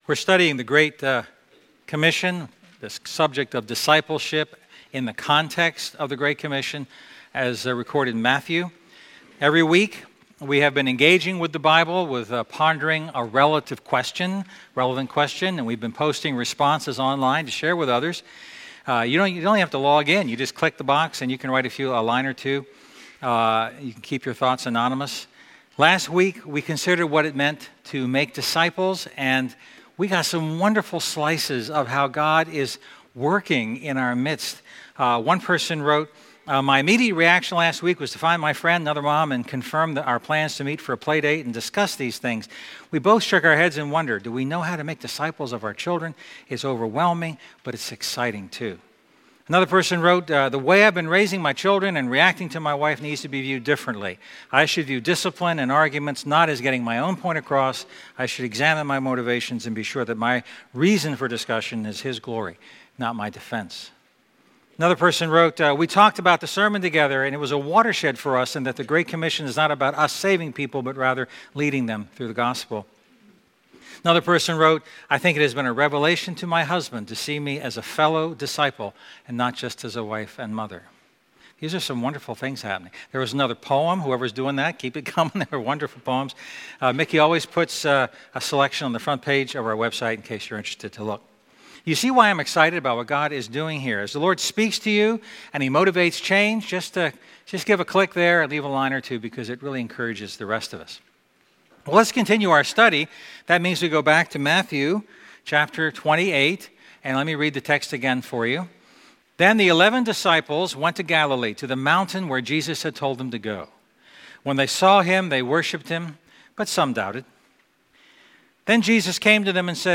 A message from the series "Discipleship."